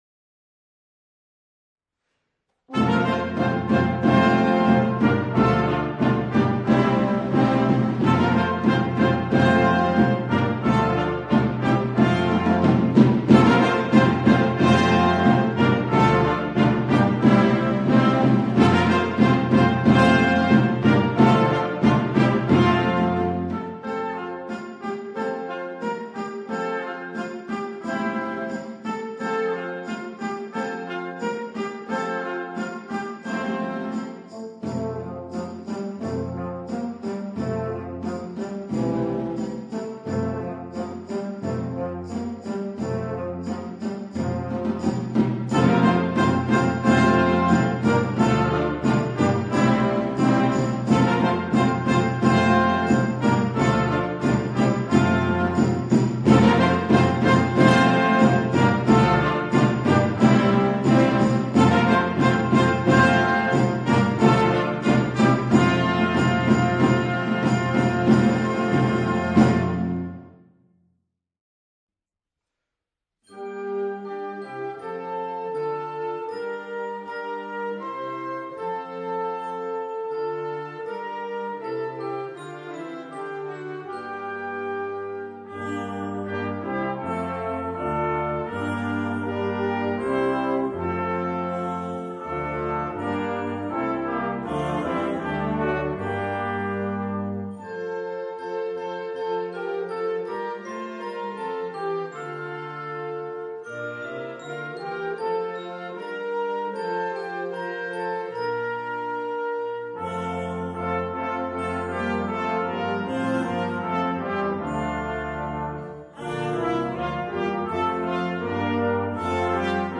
The percussion have repeated quarter and eighth notes.
Noten für Brass Band.